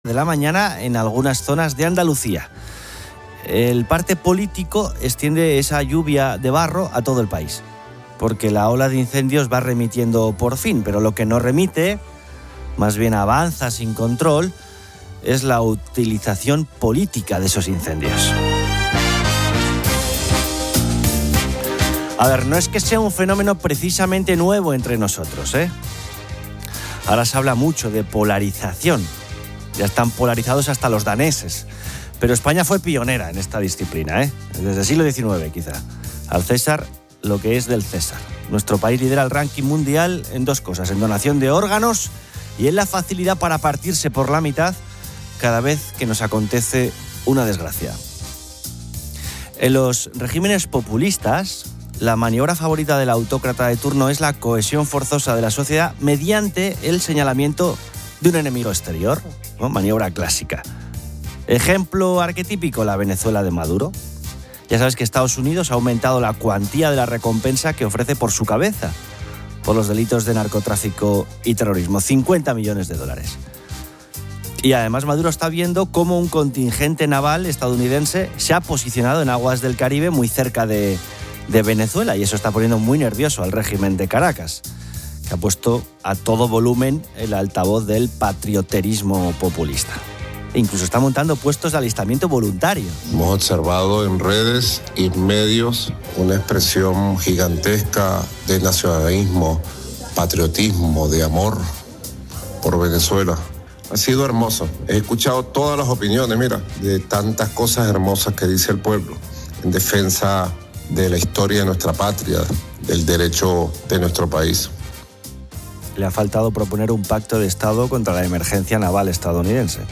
Se escuchan noticias de incendios, rescates de migrantes y un brote de intoxicación.